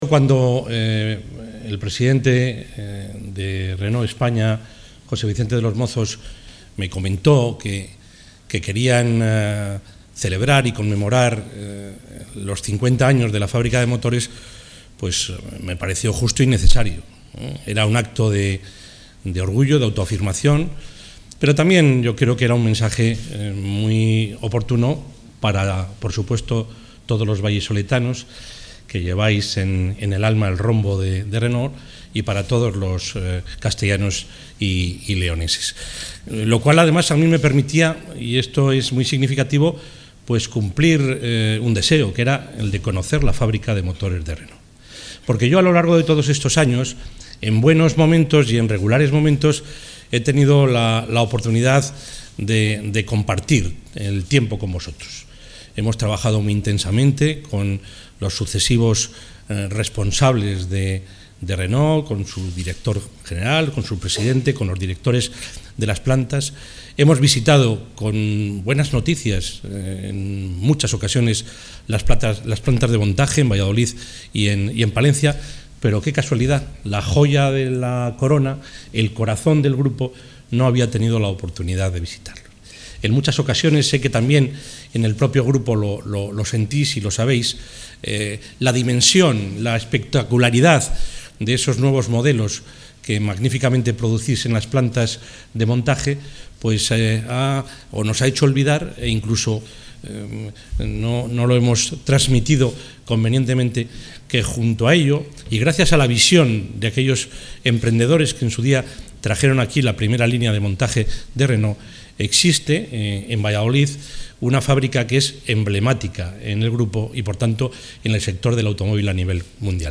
El presidente de la Junta de Castilla y León, Juan Vicente Herrera ha asistido hoy al acto de celebración del 50 Aniversario de la...